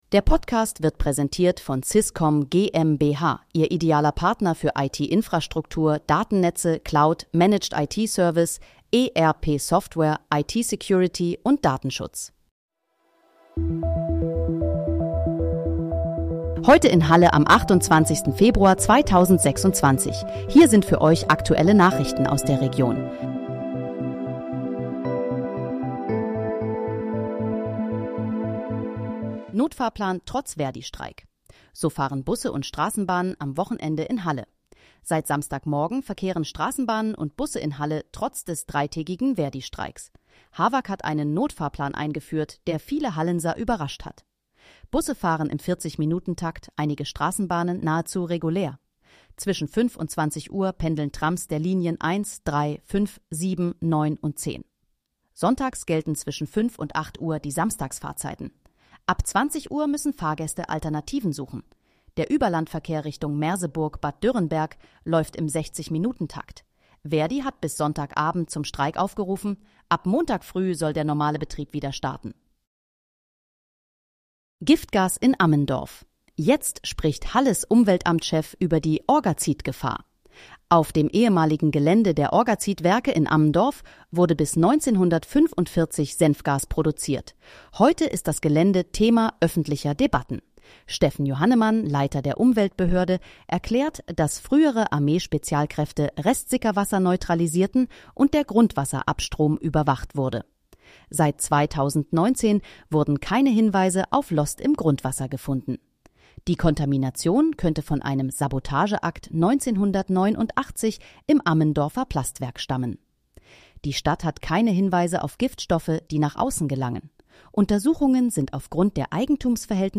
Heute in, Halle: Aktuelle Nachrichten vom 28.02.2026, erstellt mit KI-Unterstützung